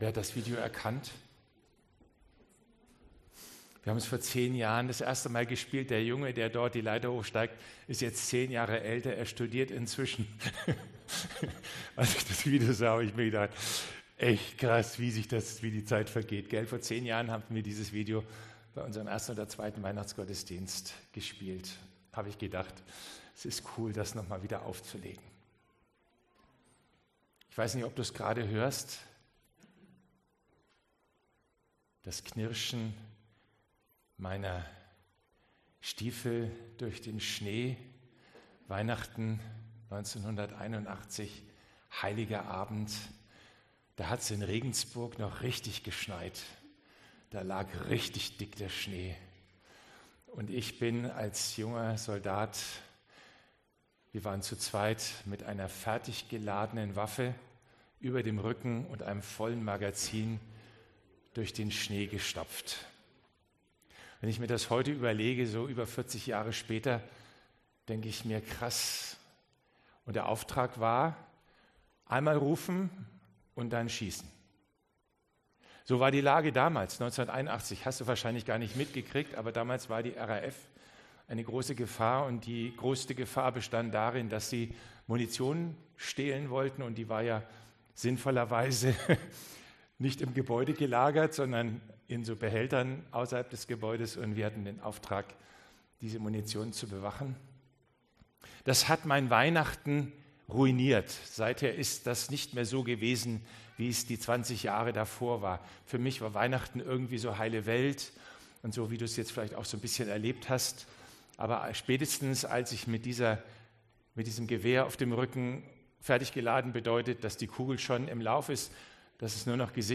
Weihnachtsgottesdienst 2023
Kategorien: Predigt